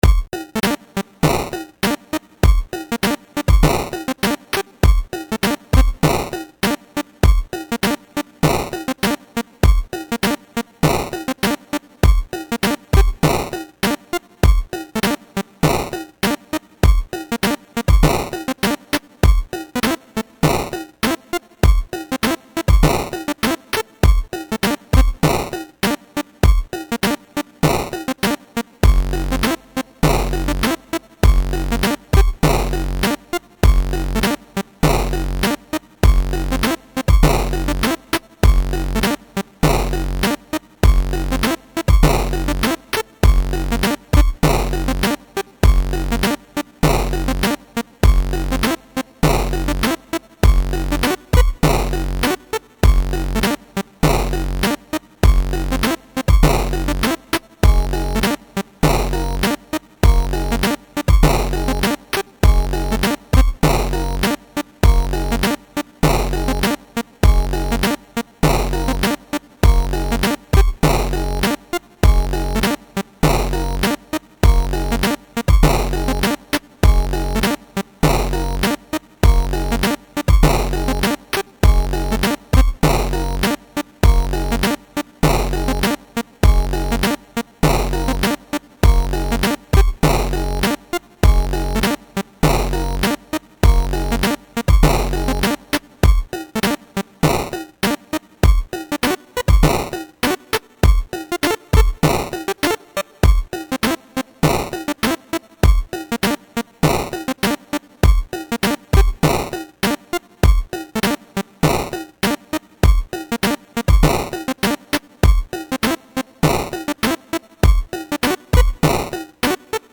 I love reggae and chiptunes so here is some reggae/chiptunes/ska stuff.